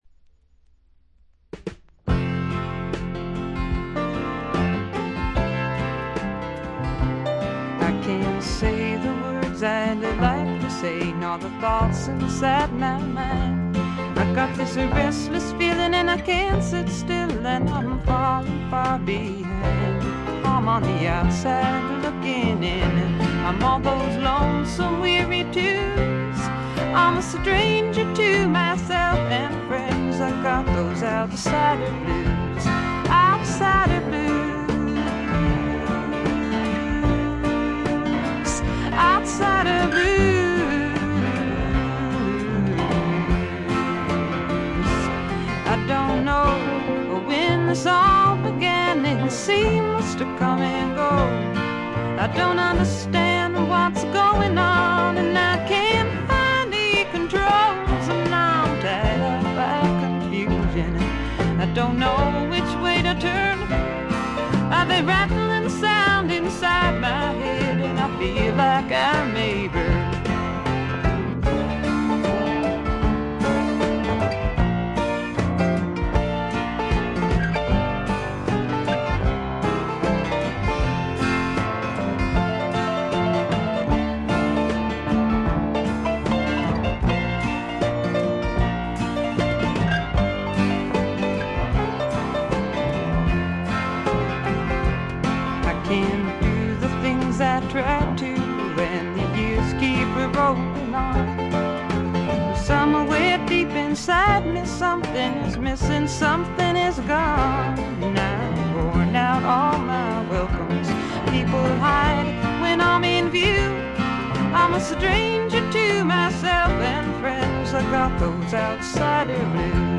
女性シンガー・ソングライター、フィメール・フォーク好きには必聴／必携かと思います。
試聴曲は現品からの取り込み音源です。
Recorded at Bearsville Sound Studios, Woodstock, N.Y.
Vocals, Acoustic Guitar
Fiddle on B5